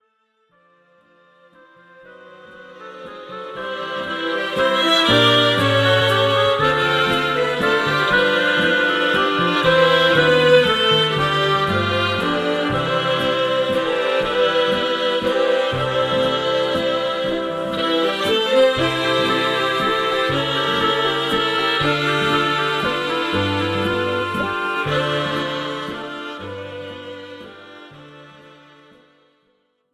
This is an instrumental backing track cover.
• Key – C
• Without Backing Vocals
• No Fade